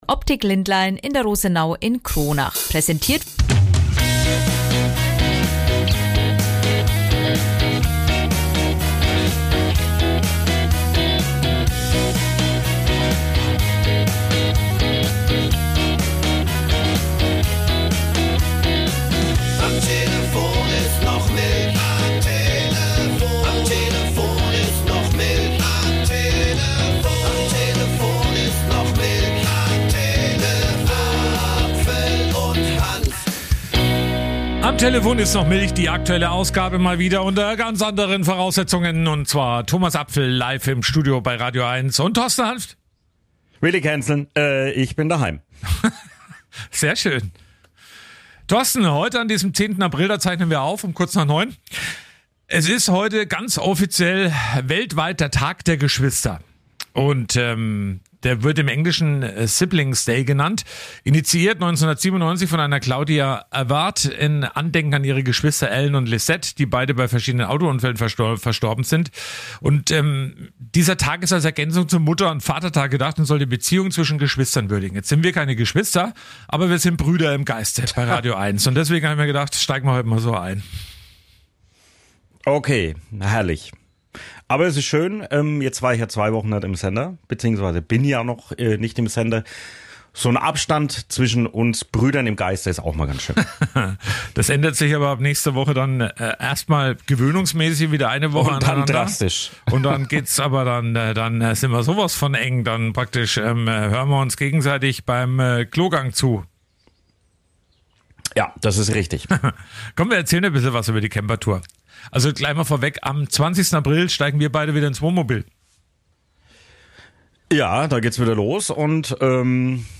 Dazu gibt es viele Berichte und Interviews